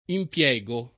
impiego [ imp L$g o ]